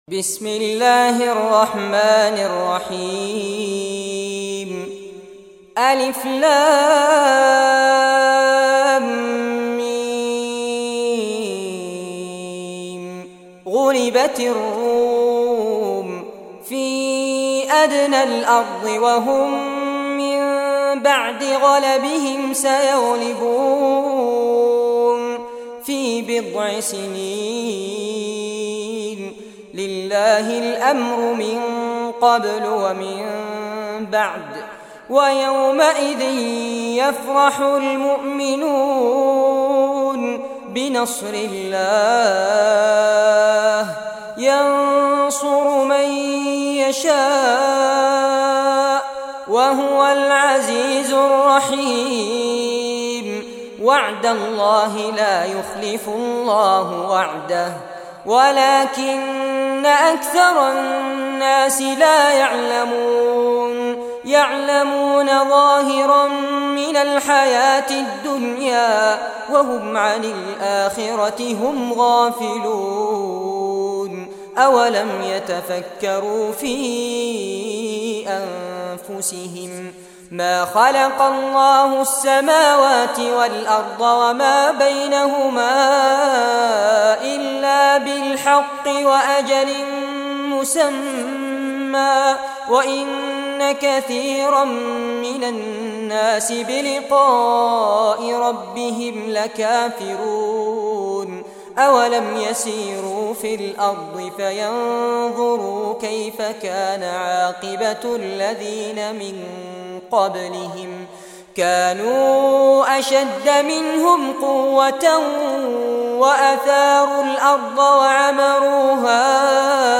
Surah Ar-Rum Recitation by Fares Abbad
Surah Ar-Rum, listen or play online mp3 tilawat / recitation in Arabic in the beautiful voice of Sheikh Fares Abbad.